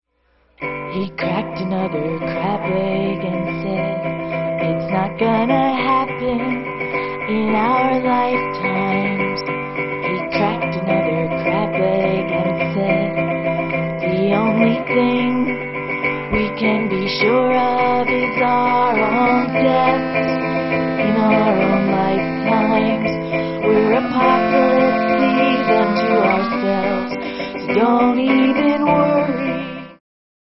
violin & acoustic guitar
bongos and rice
live at Komotion, San Francisco